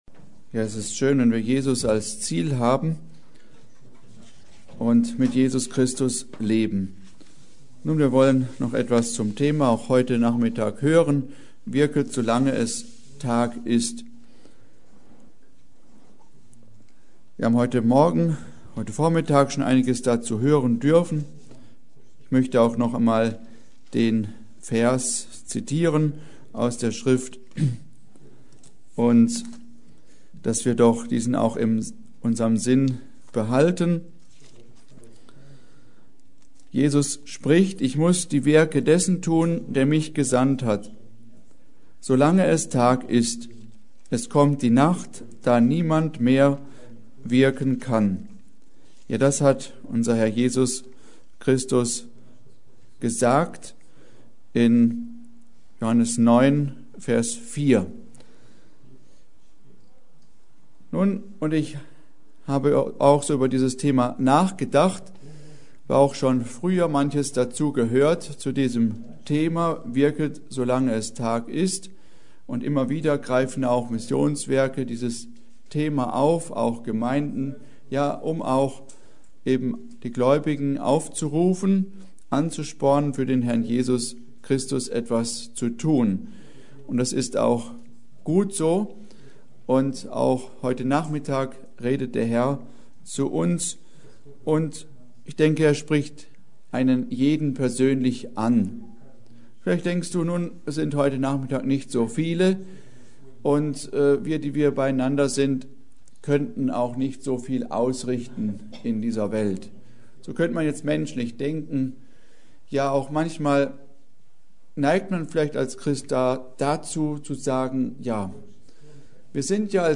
Predigt: Wirket solange es Tag ist!
Die Predigt wurde anlässlich des Missionsfestes im Missionshaus in Flehingen gehalten.